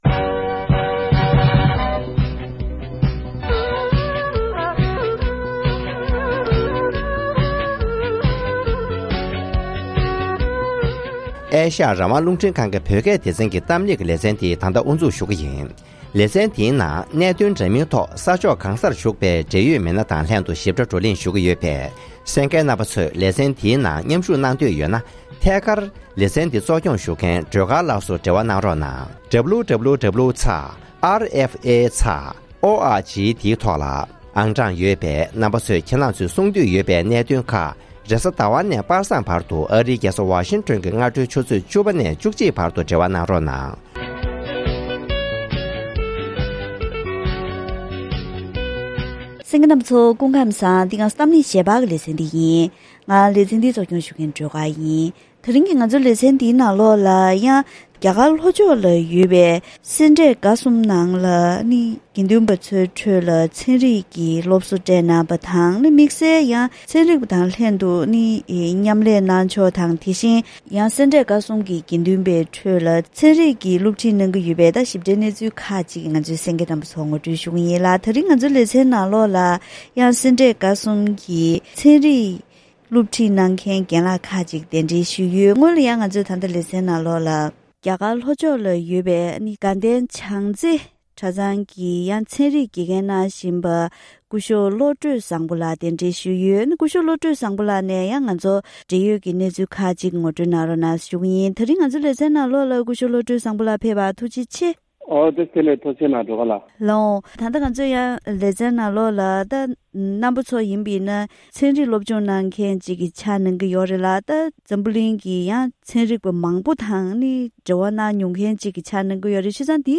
༄༅༎དེ་རིང་གི་གཏམ་གླེང་ཞལ་པར་ལེ་ཚན་ནང་བཙན་བྱོལ་ལ་ཡོད་པའི་རྒྱ་གར་གྱི་སེ་འབྲས་དགའ་གསུམ་ནང་དགེ་འདུན་པ་ཚོའི་ཁྲོད་ནང་ཆོས་སློབ་གཉེར་དང་དུས་མཚུངས་དེང་དུས་ཀྱི་ཚན་རིག་ཤེས་བྱ་སློབ་ཁྲིད་གནང་བཞིན་ཡོད་པས།